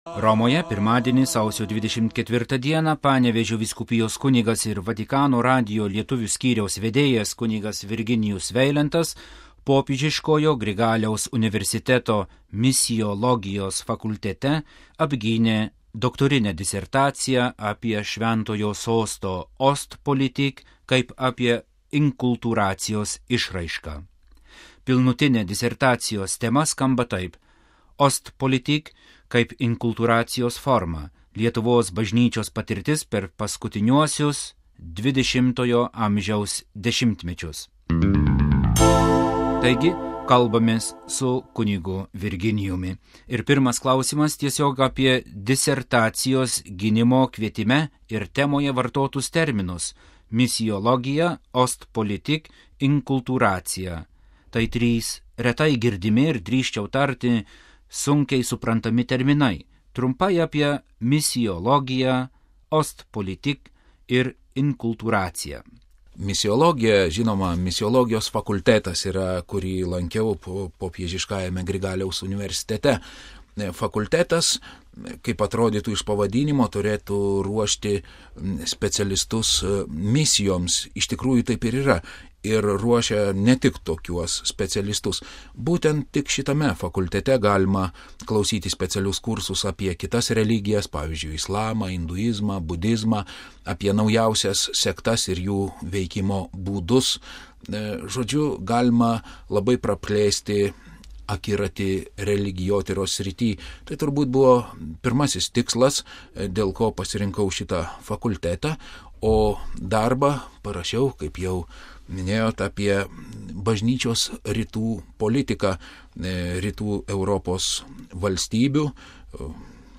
Pokalbis su kun.